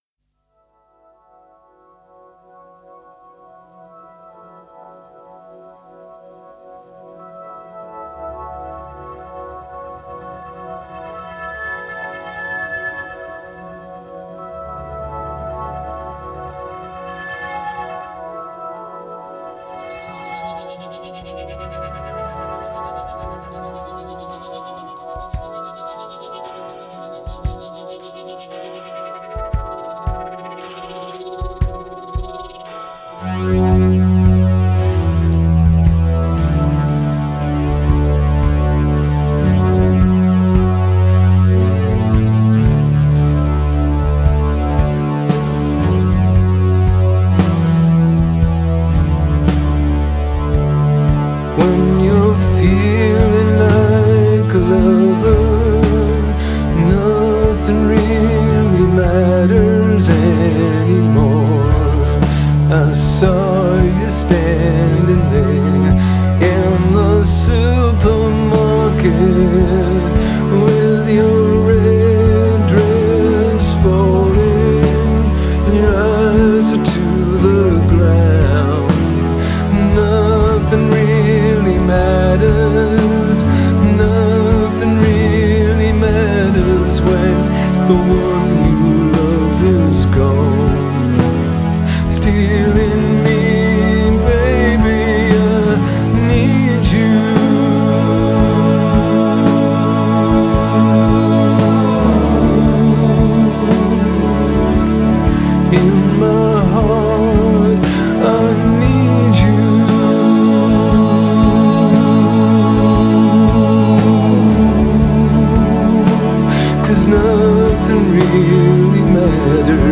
آهنگ راک